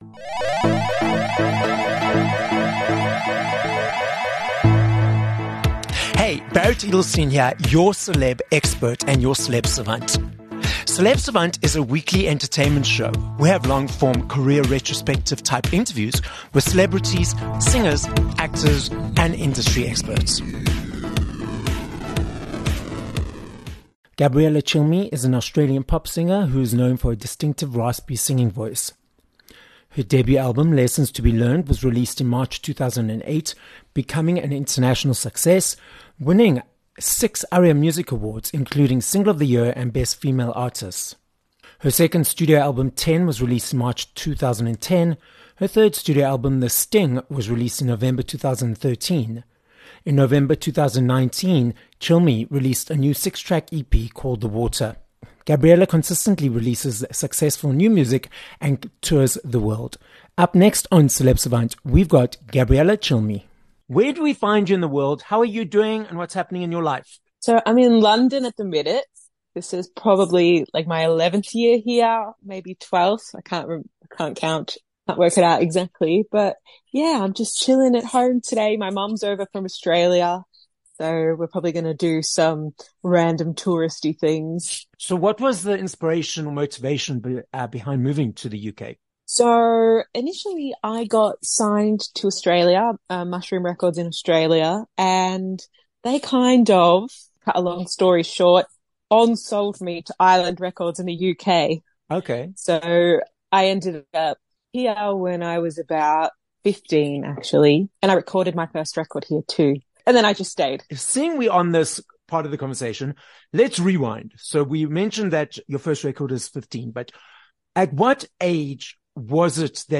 3 Aug Interview with Gabriella Cilmi